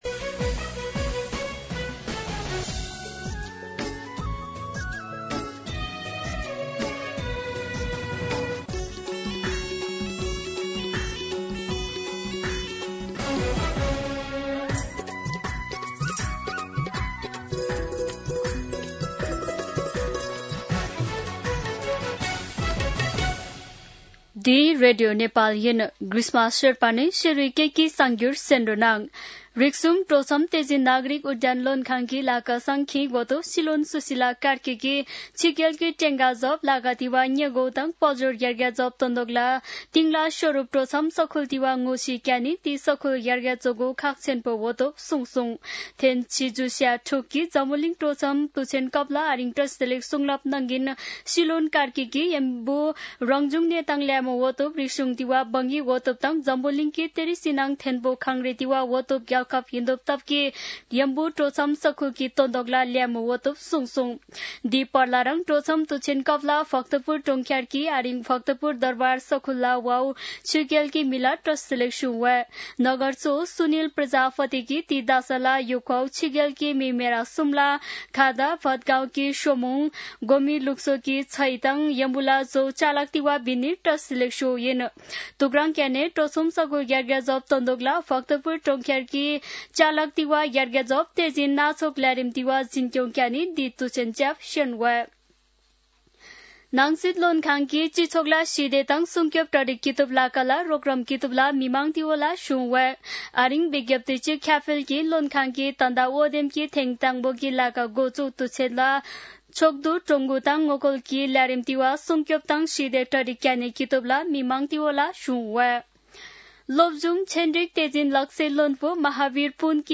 शेर्पा भाषाको समाचार : ११ असोज , २०८२
Sherpa-News-11.mp3